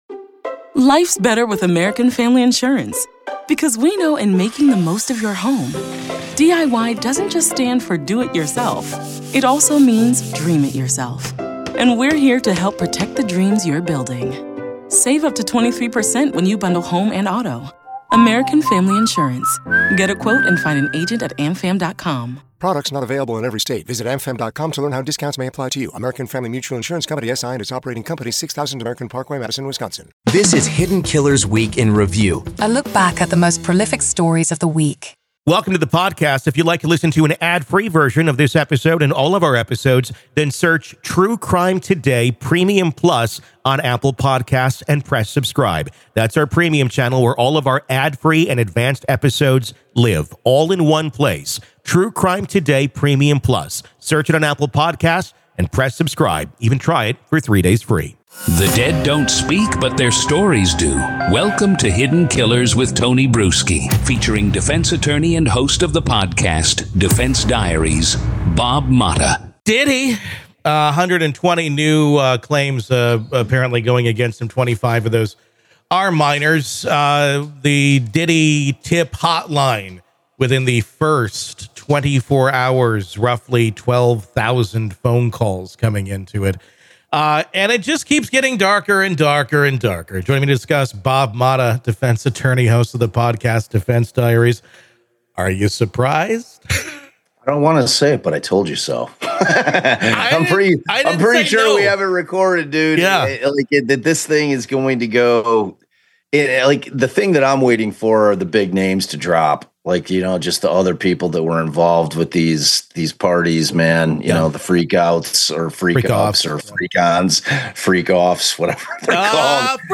From high-profile criminal trials to in-depth examinations of ongoing investigations, this podcast takes listeners on a fascinating journey through the world of true crime and current events. Each episode navigates through multiple stories, illuminating their details with factual reporting, expert commentary, and engaging conversation.